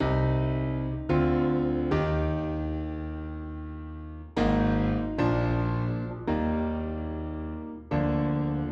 RNB钢琴110bpm Dmajor
Tag: 110 bpm RnB Loops Piano Loops 1.47 MB wav Key : D